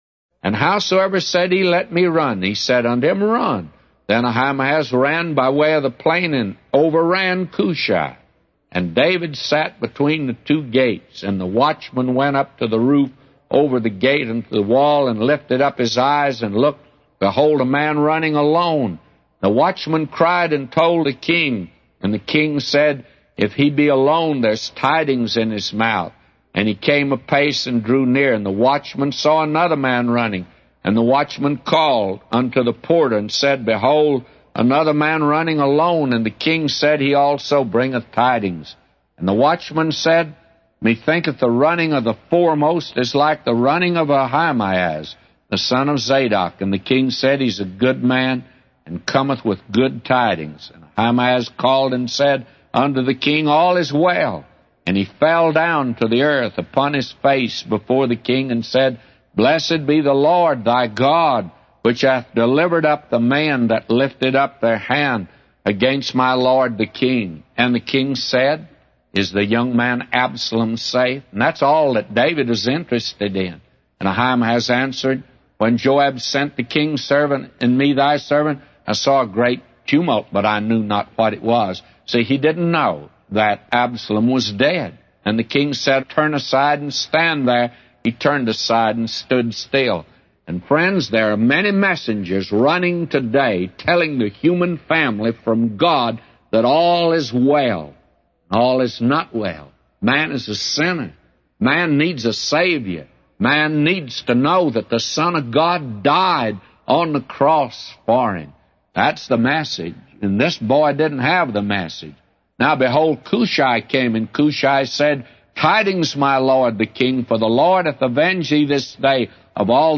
A Commentary By J Vernon MCgee For 2 Samuel 18:23-999